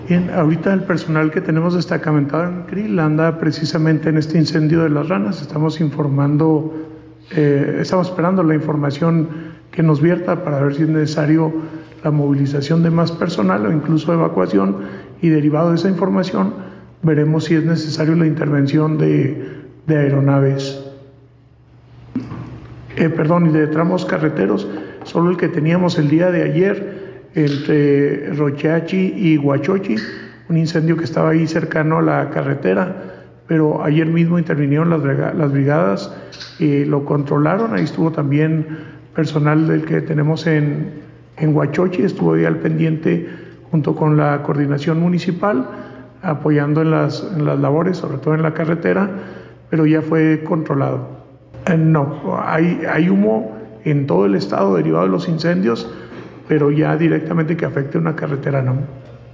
Interrogado sobre si el Gobierno posee información al respecto, Luis Corral Torresdey, titular de la Coordinación Estatal de Protección Civil (CEPC), dio a conocer que el personal destacamentado en el municipio de Creel trabaja en el análisis de la zona para determinar una situación de riesgo para la población que vive en los alrededores, así como para quienes transiten por las carreteras aledañas.